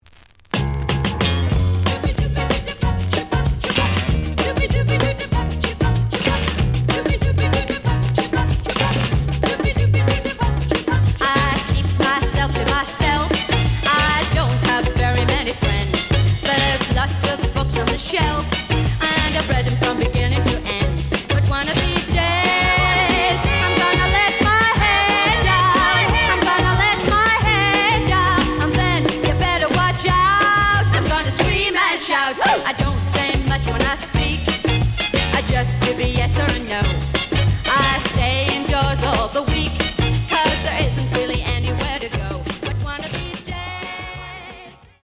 ●Genre: Rhythm And Blues / Rock 'n' Roll